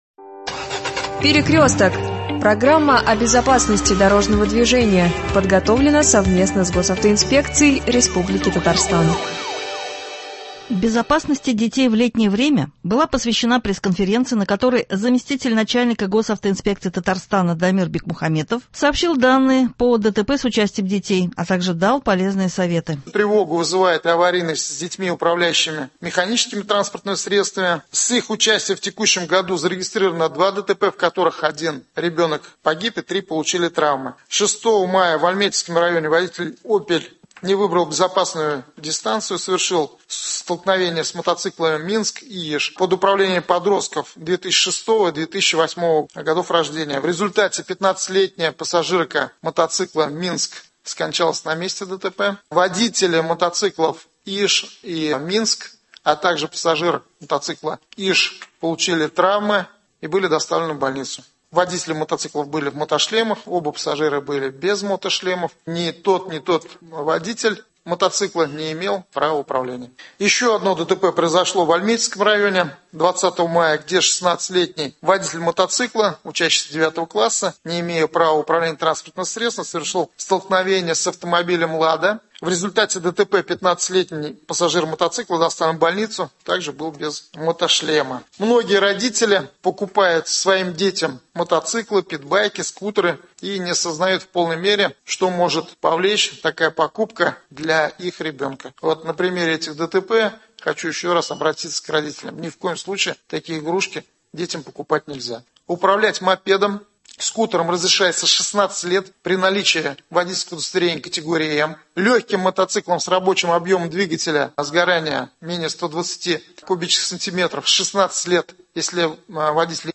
Безопасности детей в летнее время была посвящена пресс- конференция , на которой заместитель начальника Госавтоинспекции Татарстана Дамир Бикмухаметов сообщил данные по ДТП с участием детей и дал полезные советы.